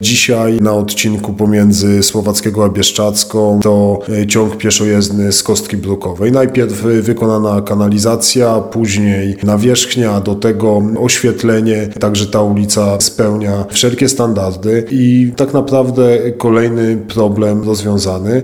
Jeszcze jesienią zeszłego roku ta ulica miała nawierzchnię gruntową co utrudniało korzystanie z niej mieszkańcom okolicznych domów, mówi wiceprezydent Radomia Mateusz Tyczyński: